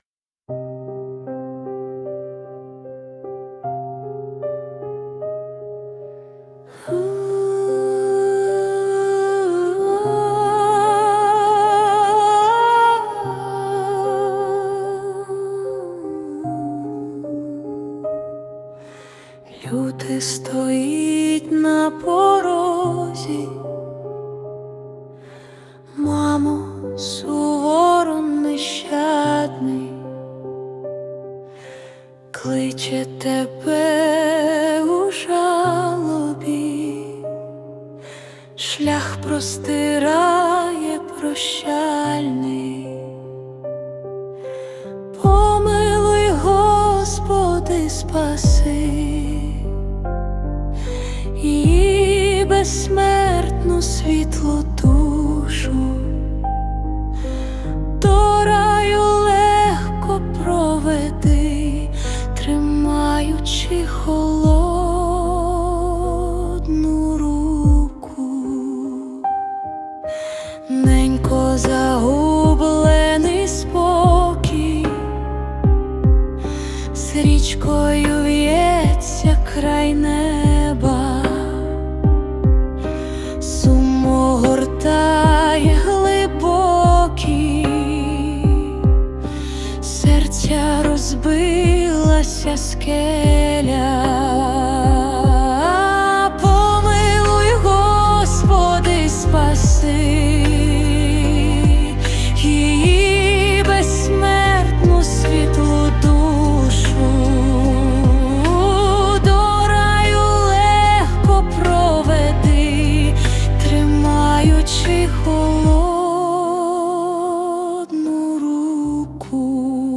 твір авторський..музика і виконання ШІ
СТИЛЬОВІ ЖАНРИ: Ліричний